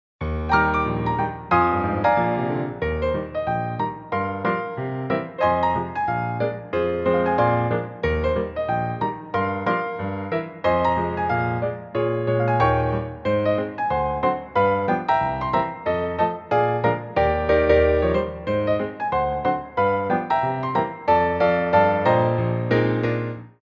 Mediuem Allegro 2
4/4 (16x8)